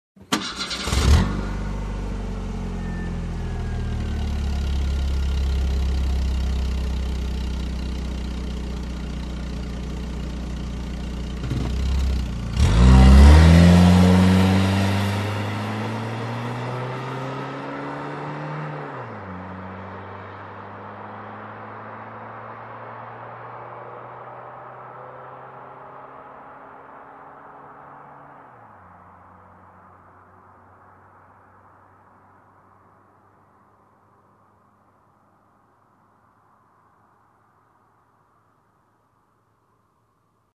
Звуки автомобиля
Автомобиль заводится и стремительно уезжает